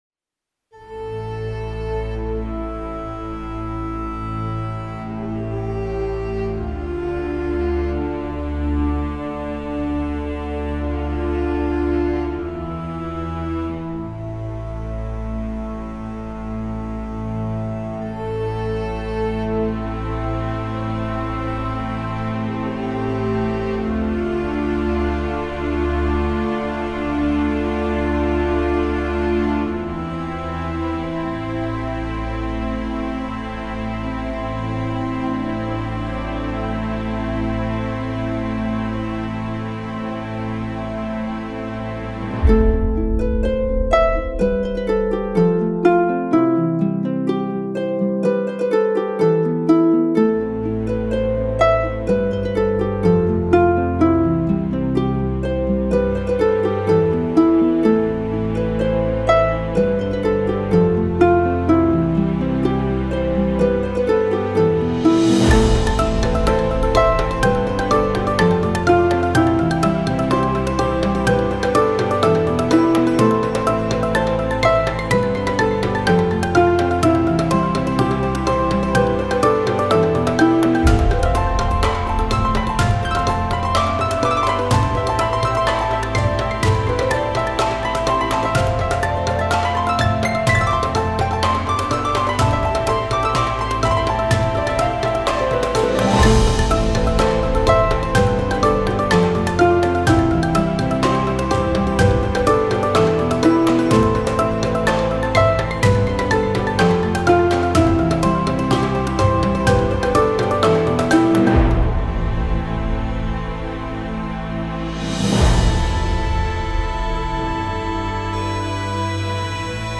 轻松愉悦